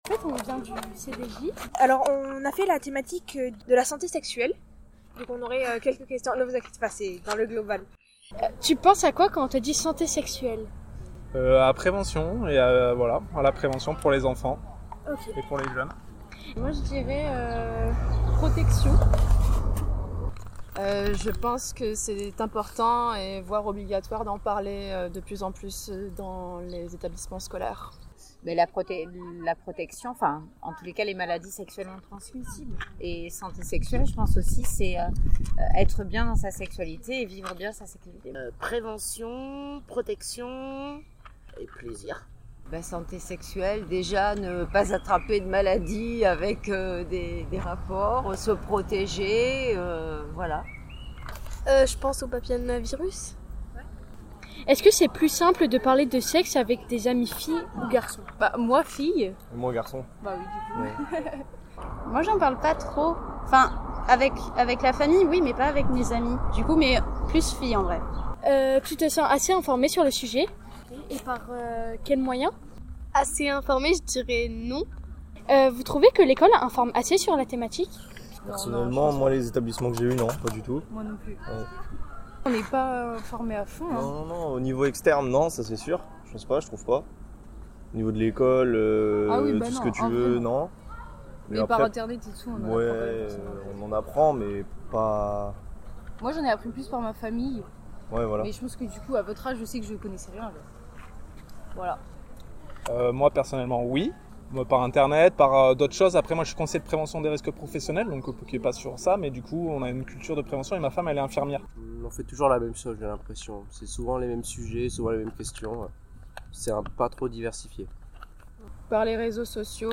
Dans une idée de suite de l’année de Grande Cause Départementale 2023 consacrée à la Jeunesse, la commission Concertation Politiques Jeunesse est allée à la rencontre de passants en réalisant deux micros-trottoirs : un premier sur la santé sexuelle et un second la santé mentale des jeunes.
Découvrez ci-dessous les micro-trottoirs réalisés par les jeunes élus.
Micro-trottoir santé sexuelle